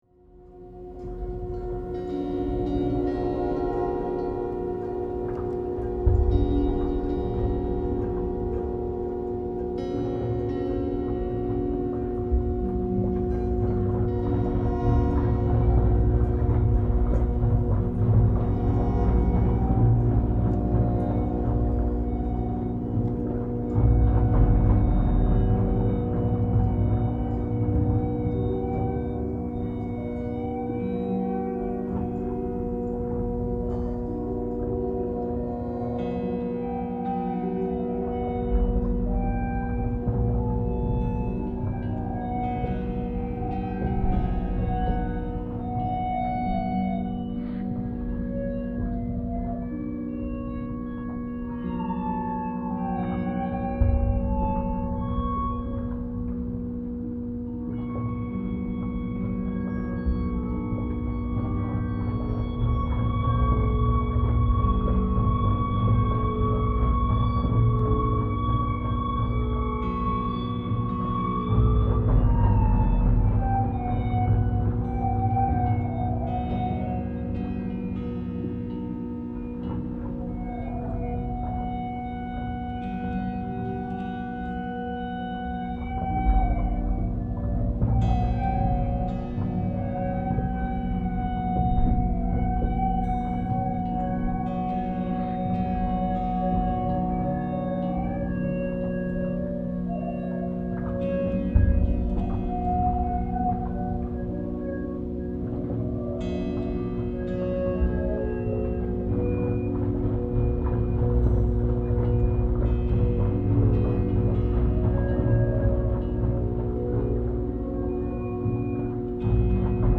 Stil: Dark Ambient, Experimental, Soundscape